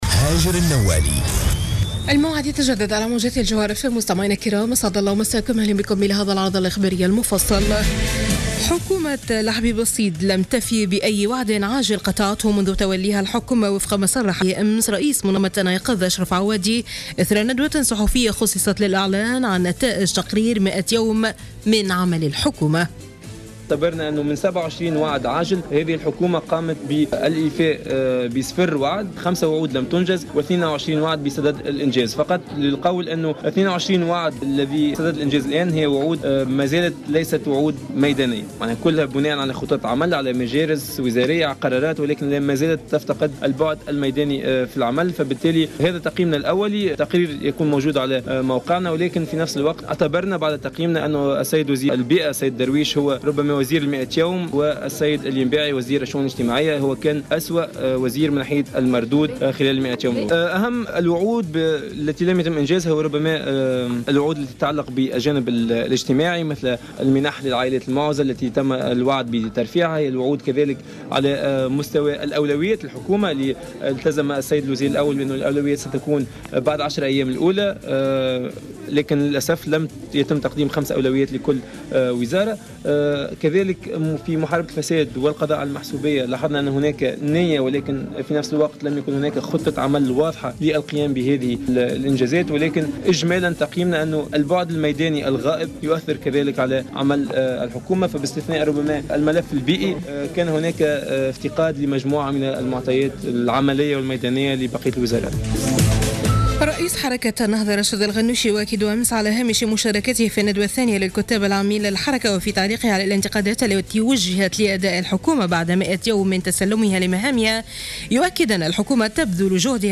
نشرة أخبار منتصف الليل ليوم الأحد 17 ماي 2015